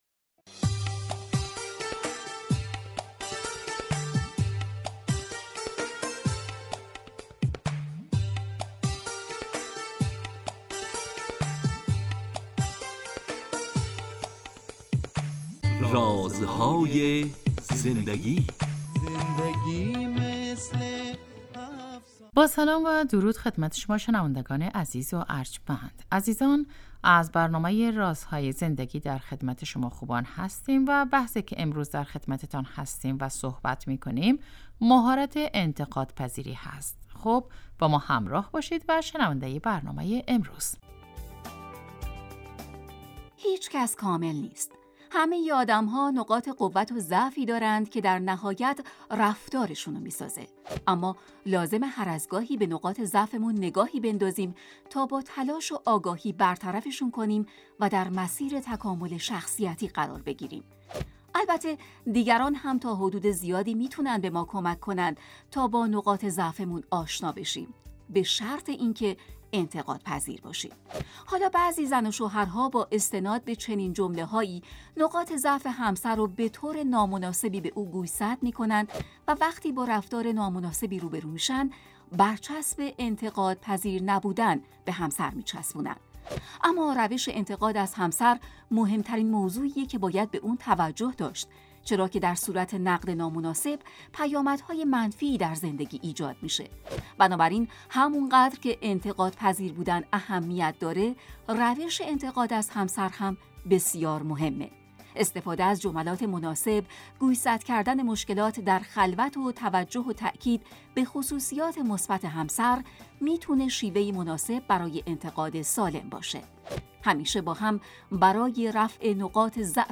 این برنامه به مدت 15 دقیقه هر روز ساعت 11:35 به وقت افغانستان از رادیو دری پخش می شود .